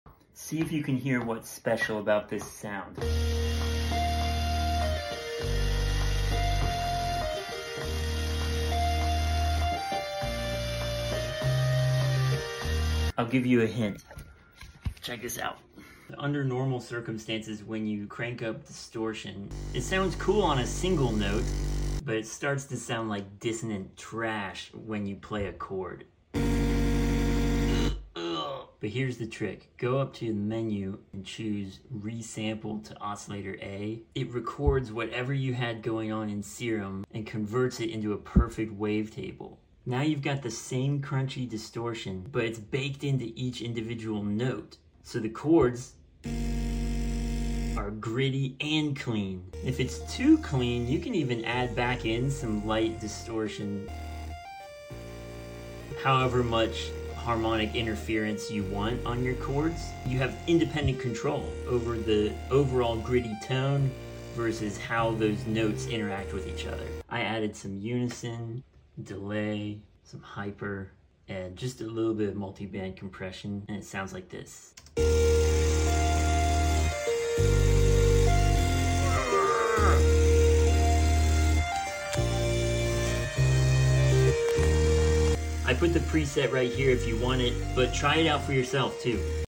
Polyphonic distortion is soooo egregiously sound effects free download